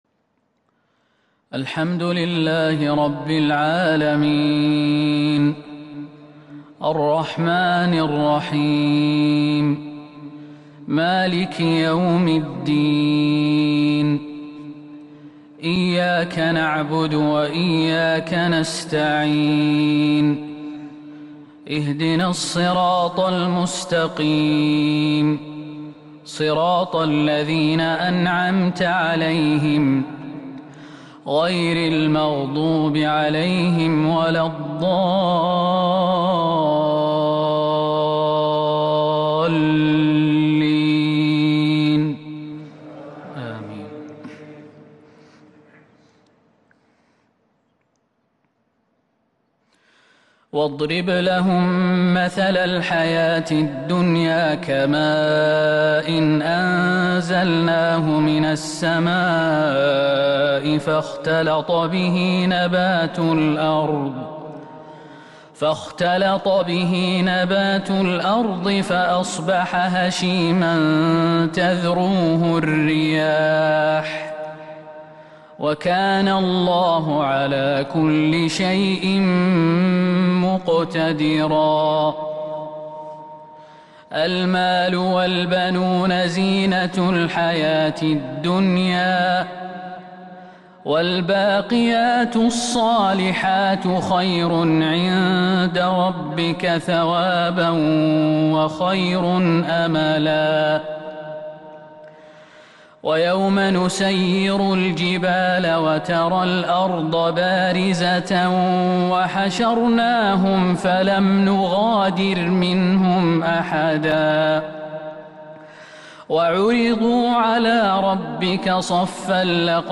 صلاة الفجر من سورة الكهف الأربعاء 22 شوال 1442هـ | fajr 3-6-2021 prayer from Surah Al-Kahf > 1442 🕌 > الفروض - تلاوات الحرمين